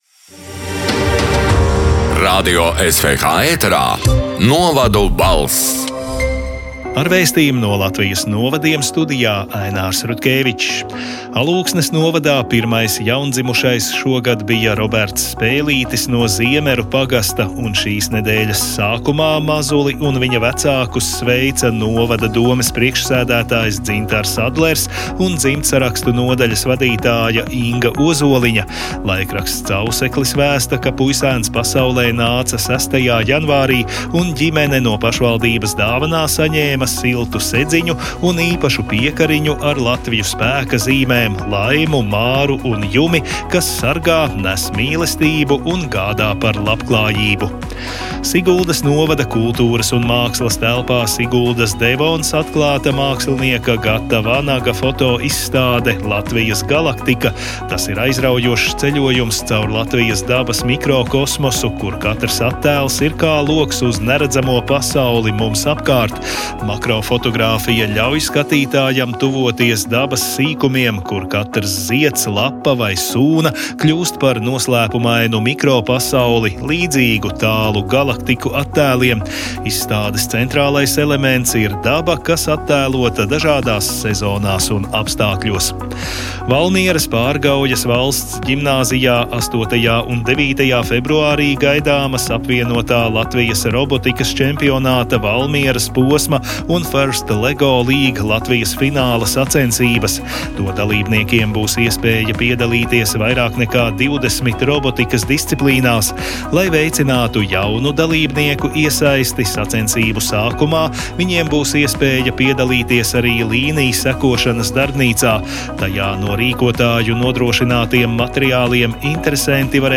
“Novadu balss” 22. janvāra ziņu raidījuma ieraksts: